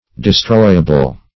Search Result for " destroyable" : Wordnet 3.0 ADJECTIVE (1) 1. capable of being destroyed ; The Collaborative International Dictionary of English v.0.48: Destroyable \De*stroy"a*ble\, a. Destructible.